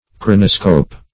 Chronoscope \Chron"o*scope\, n. [Gr. ? time + -scope.]